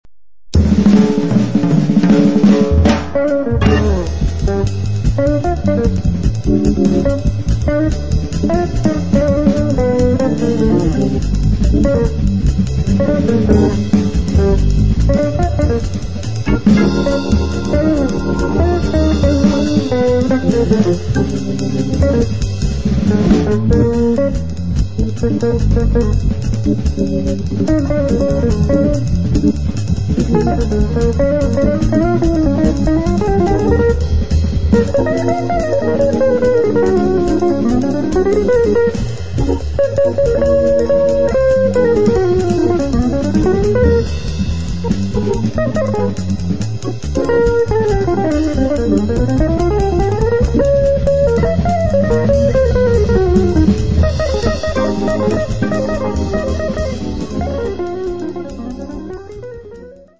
Chitarra
Hammond B3
Pianoforte
Contrabbasso
Batteria